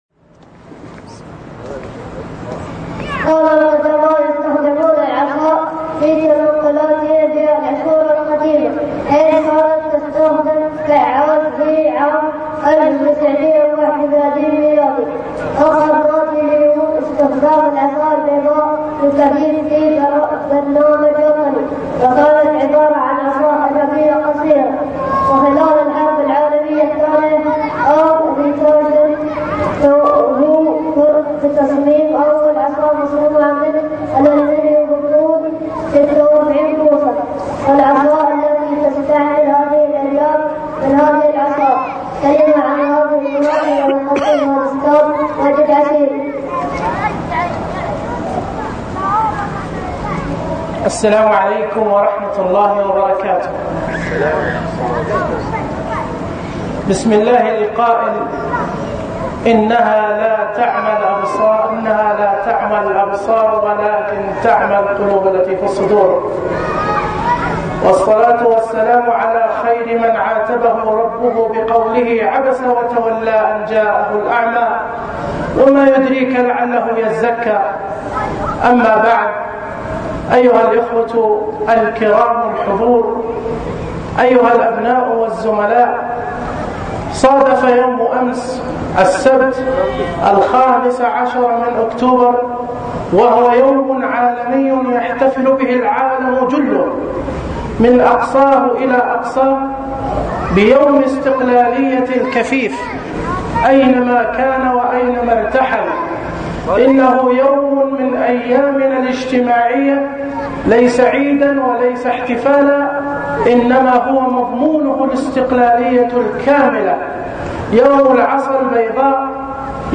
كلمة يوم التوحد